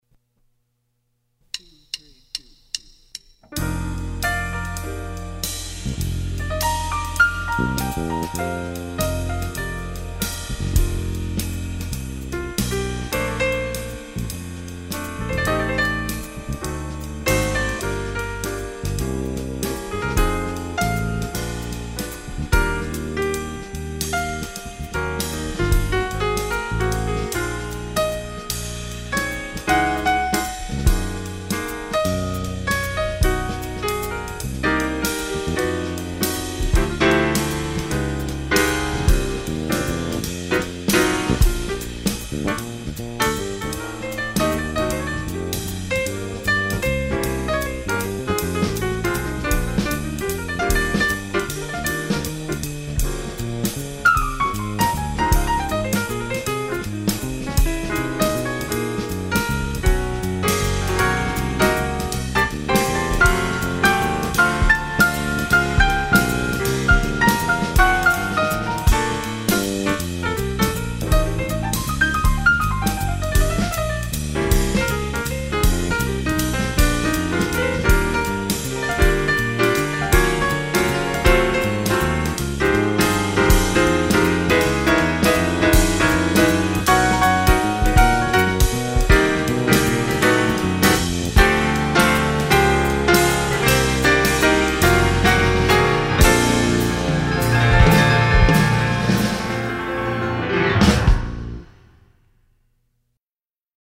"Jazz Waltz"